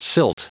Prononciation du mot silt en anglais (fichier audio)
Prononciation du mot : silt